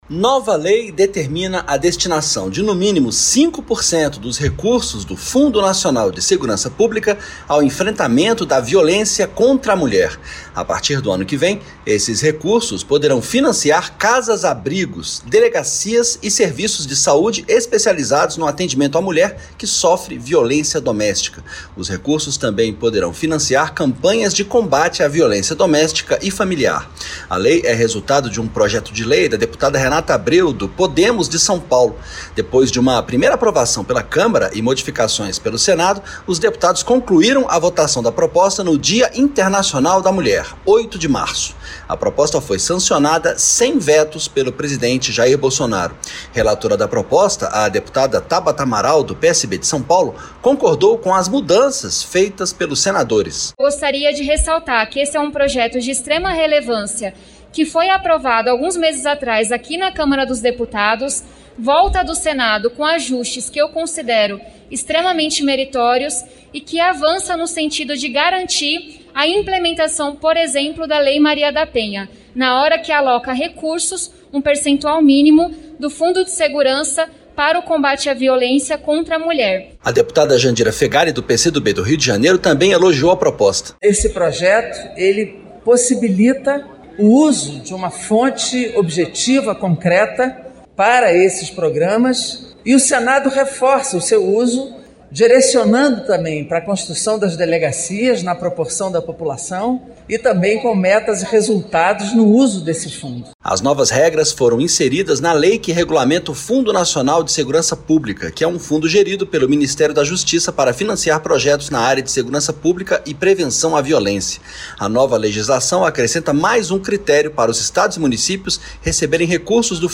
FOI SANCIONADA LEI QUE DESTINA MAIS RECURSOS PARA O ENFRENTAMENTO DA VIOLÊNCIA CONTRA A MULHER. O REPÓRTER